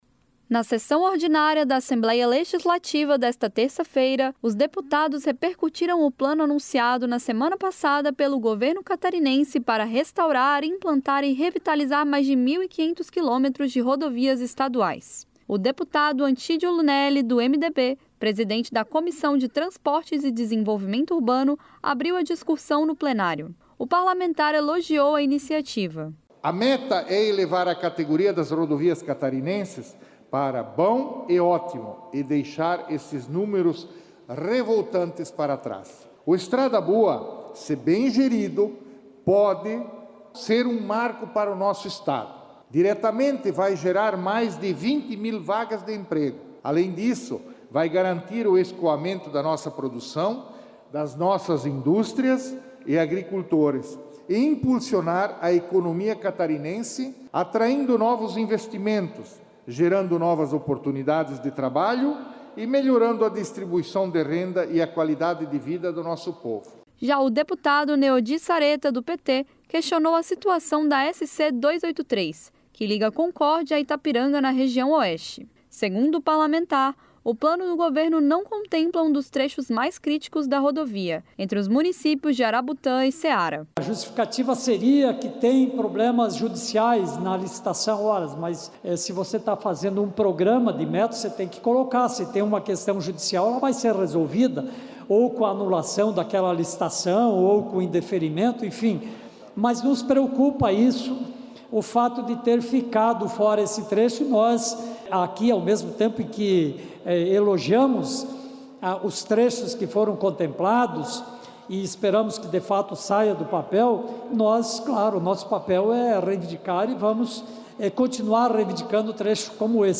Entrevistas com:
- deputado Antídio Lunelli (MDB), presidente da Comissão de Transportes e Desenvolvimento Urbano;
- deputado Neodi Saretta (PT).